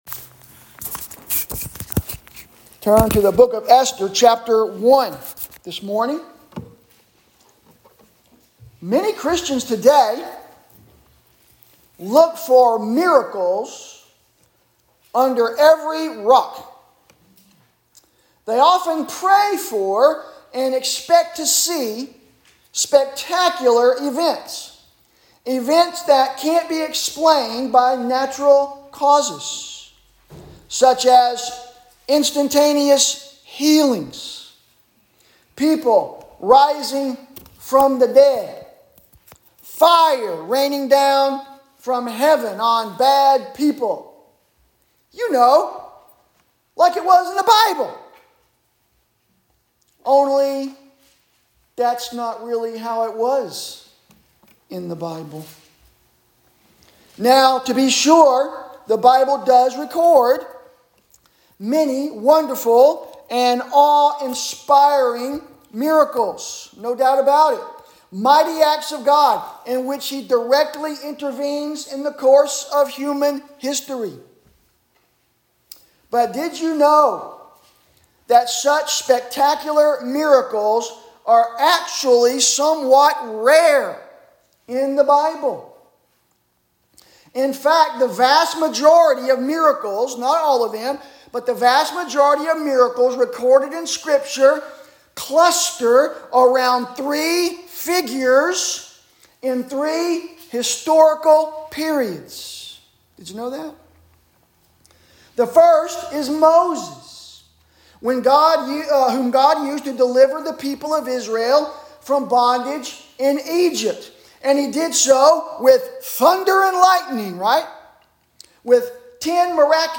Sermons – First Baptist Church